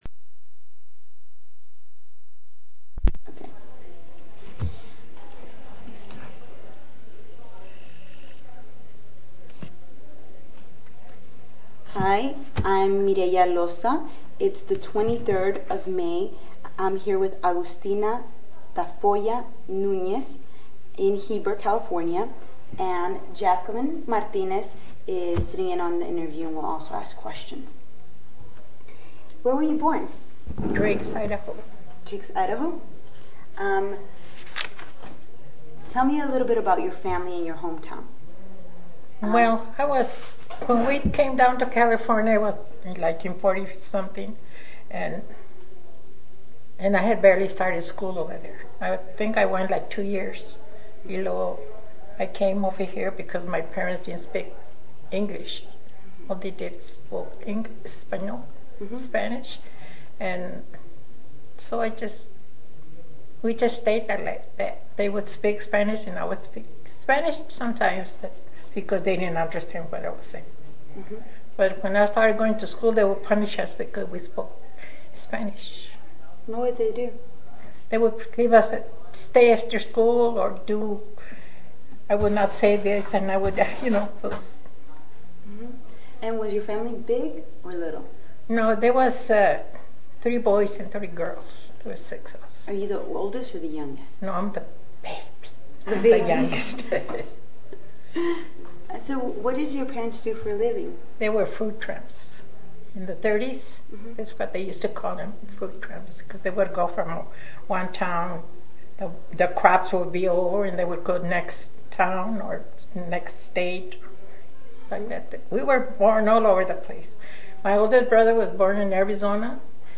Location Heber, CA